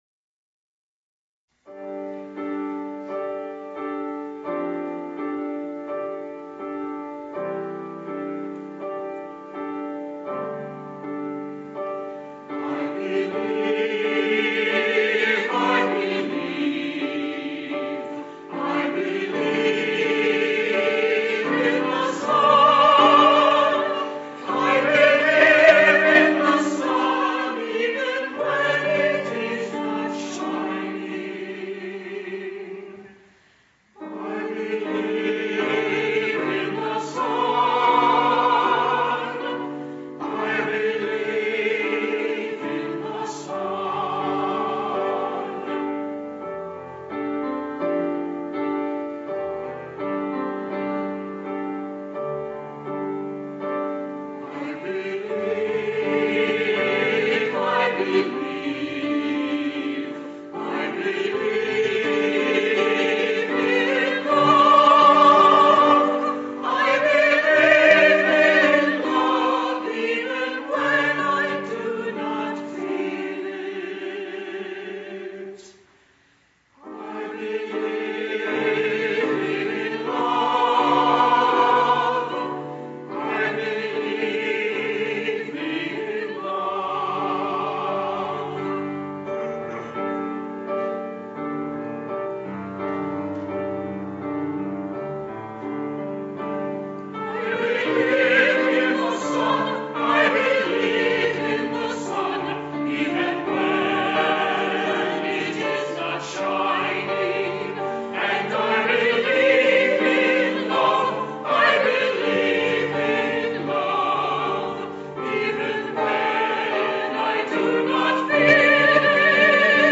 Anthems